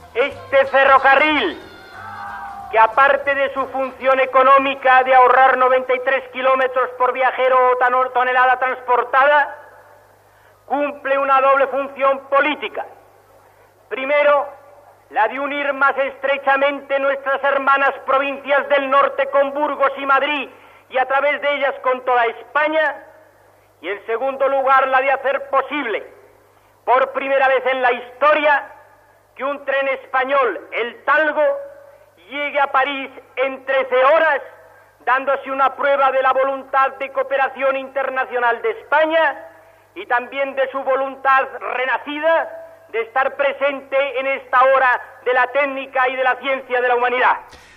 Discurs del ministre d'Obres Públiques, Federico Silva Muñoz, el dia de la inauguració del TALGO (Tren Articulado Ligero Goicoechea Oriol) Madrid París
Informatiu